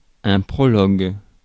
Ääntäminen
Synonyymit exorde Ääntäminen France: IPA: /pʁɔ.lɔɡ/ Haettu sana löytyi näillä lähdekielillä: ranska Käännös 1. prólogo {m} Suku: m .